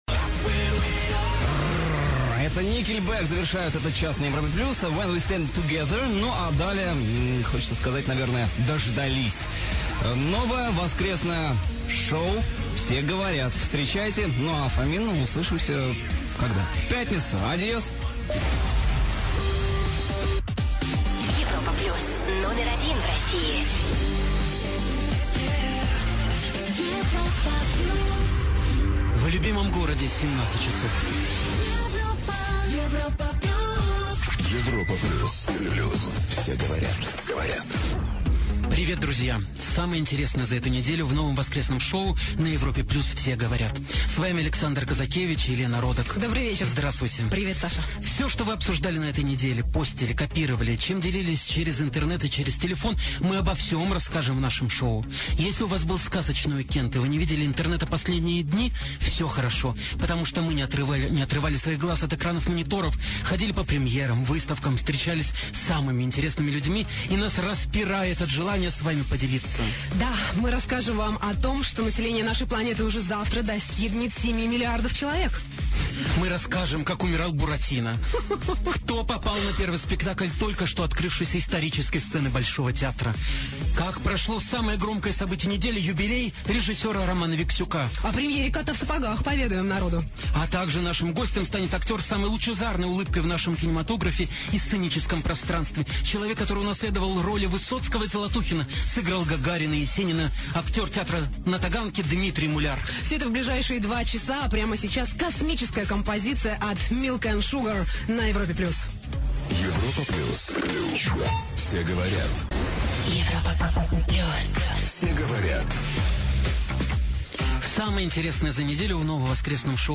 Новое шоу выходного дня на "Европе Плюс" "Все говорят". Запись эфира.
В эфире "Европы Плюс" появилось новое вечернее шоу "Все говорят". Первый выпуск шоу, которое будет выходить по воскресеньям с 17 часов.